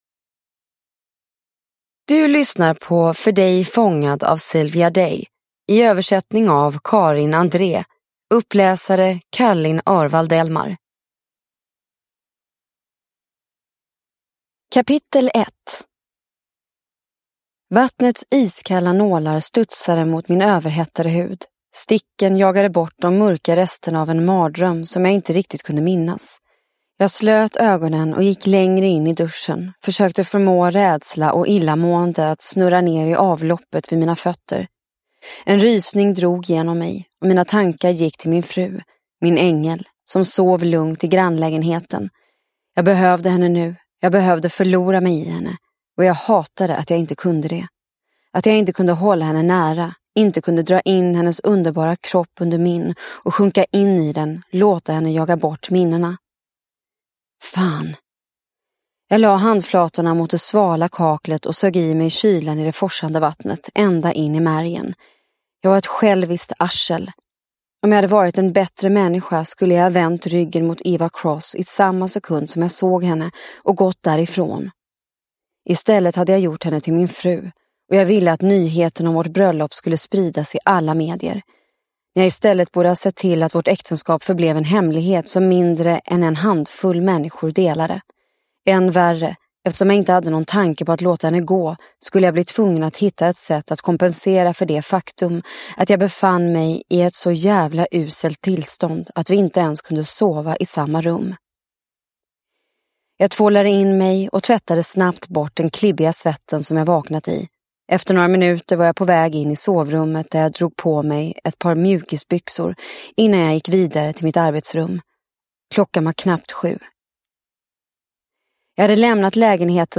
För dig fångad – Ljudbok – Laddas ner